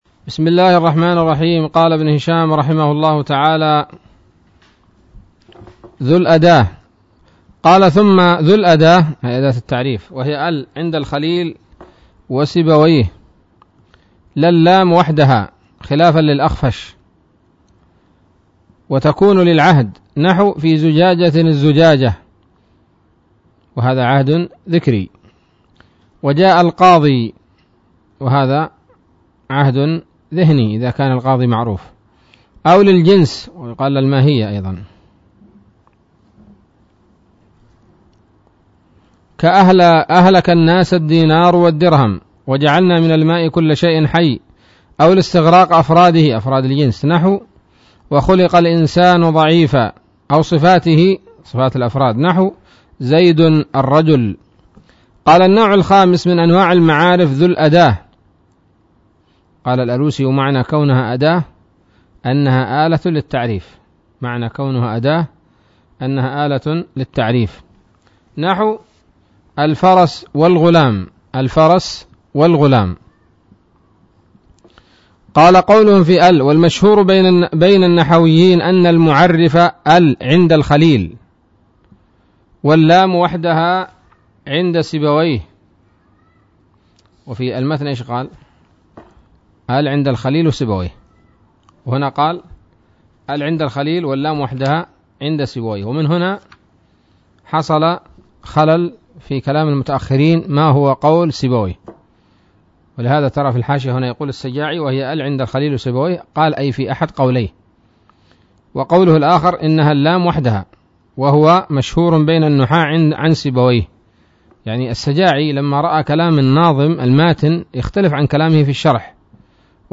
الدرس التاسع والأربعون من شرح قطر الندى وبل الصدى